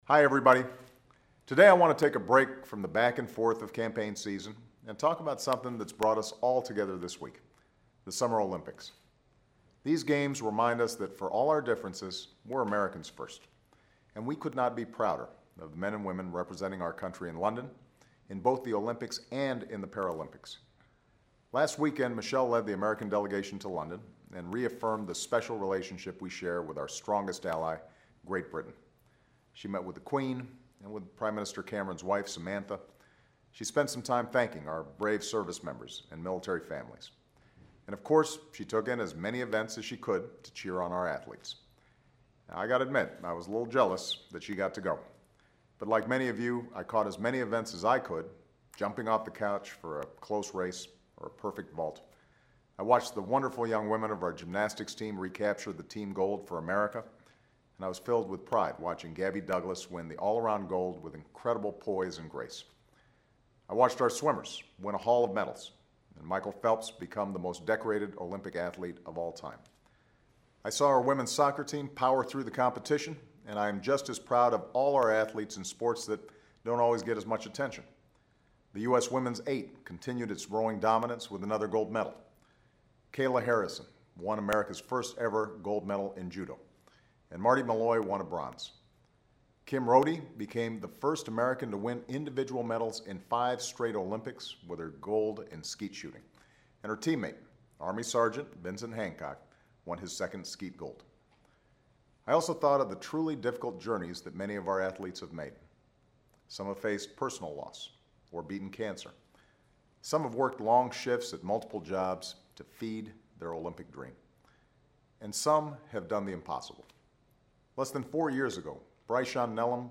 Weekly Address: Celebrating the Summer Olympics and Paralympics